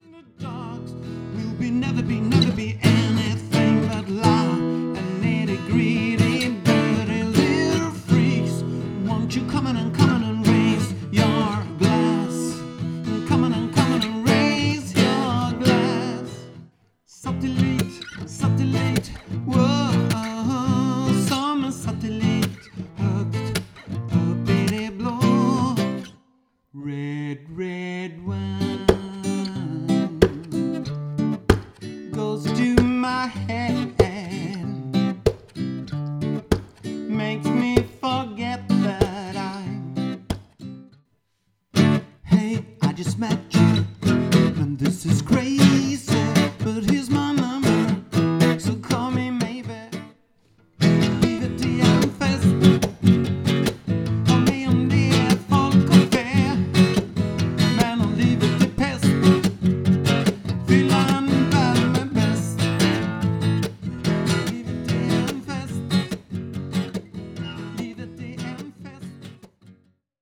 Erfaren trubadur som spelar mingelmusik, covers och allsång.
• blandad trubadurmusik (Trubadur-medley)